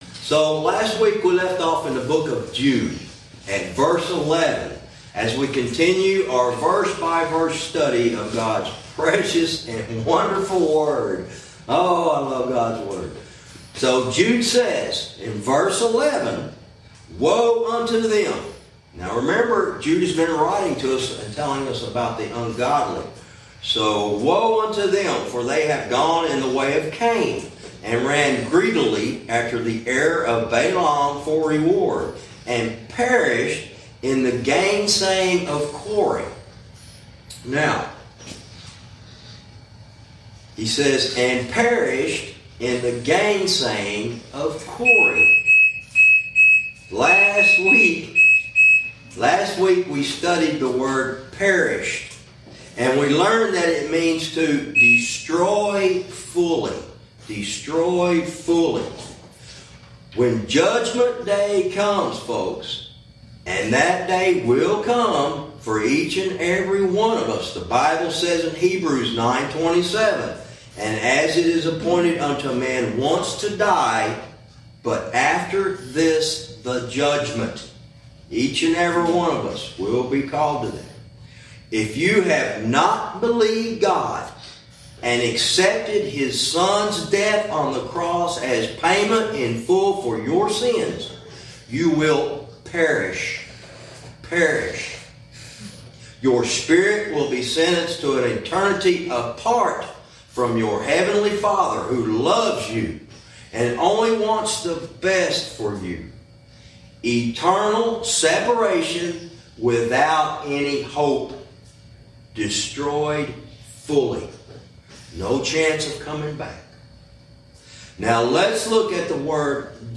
Verse by verse teaching - Lesson 45 verse 11